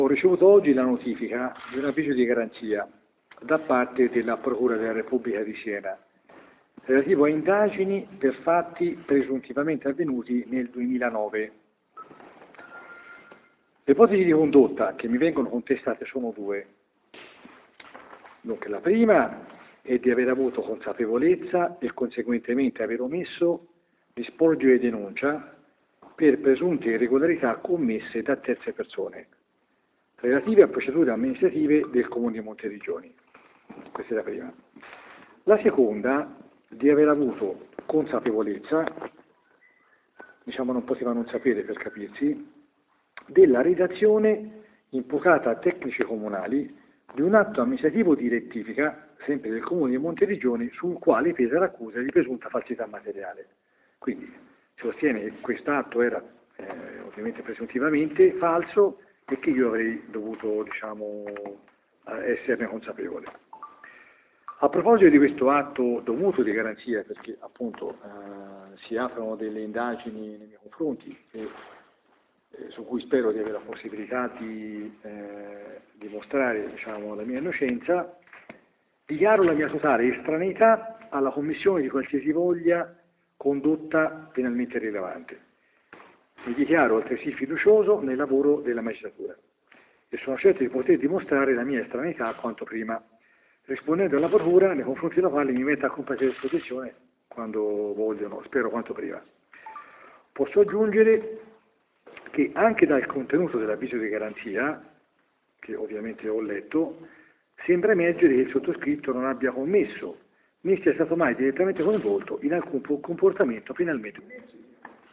Nel pomeriggio, Valentini ha indetto una conferenza stampa in cui non ha risposto alle domande dei giornalisti ma ha letto il comunicato di cui, di seguito, riportiamo un estratto.